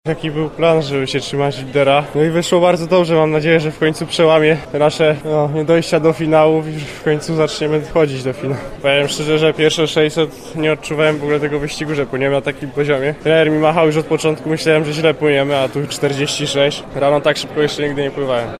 Mówi Wojciech Wojdak.